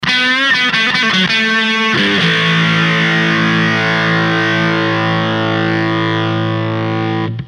で、いつもの安いトランジスタアンプで音出しです。
かなり歪ませ気味で行きます。
DISTORTION WOLF ON (90kbMP3)
ね？音出たでしょ？歪んだでしょ？